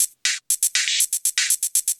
Index of /musicradar/ultimate-hihat-samples/120bpm
UHH_ElectroHatA_120-01.wav